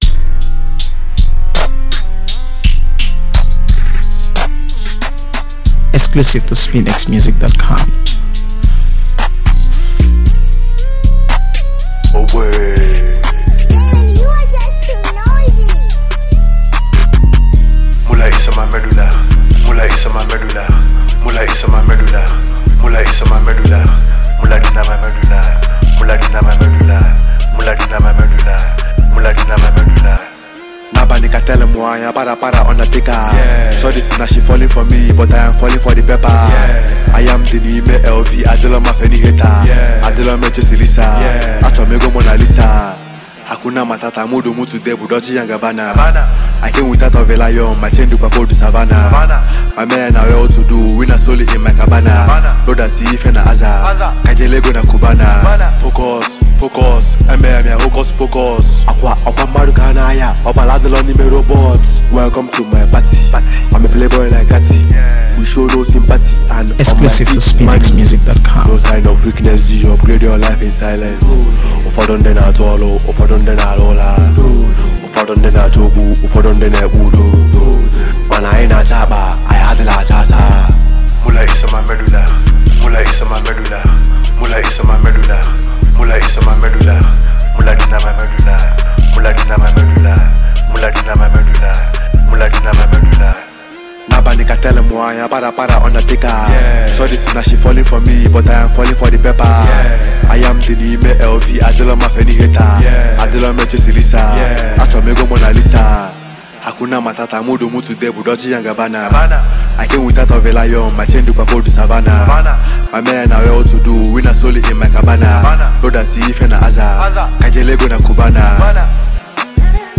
AfroBeats | AfroBeats songs
Nigerian rapper-songwriter and musician